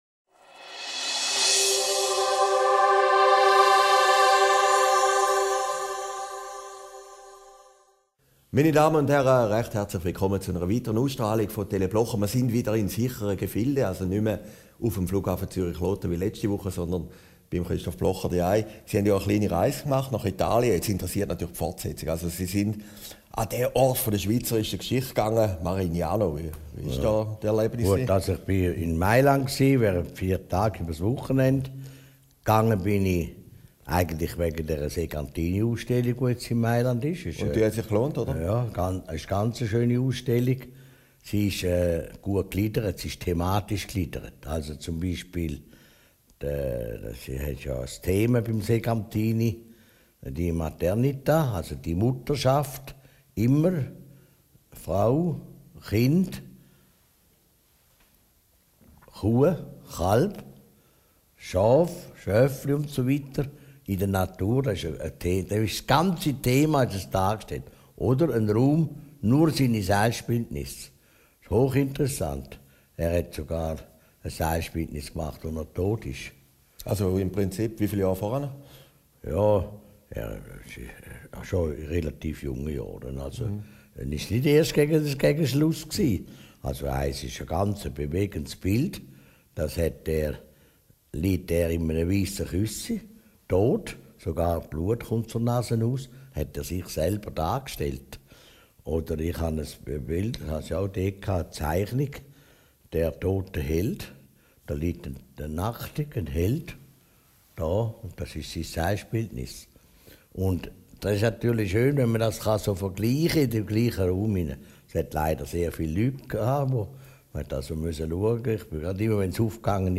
Aufgezeichnet in Herrliberg, 21. November 2014